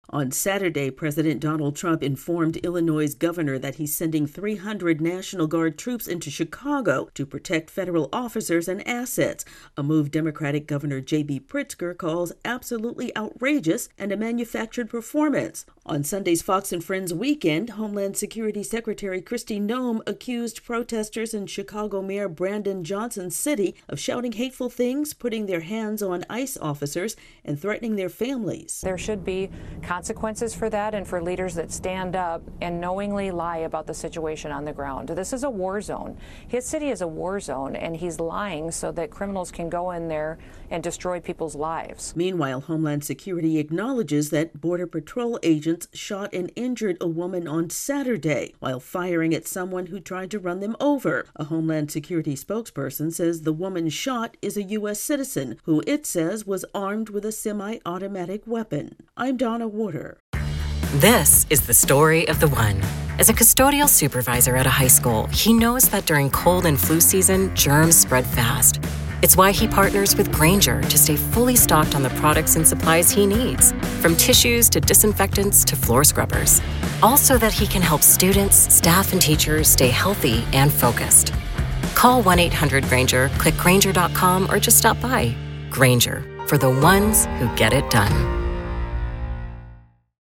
The White House says ongoing lawlessness is why its deploying National Guard members to Chicago. AP correspondent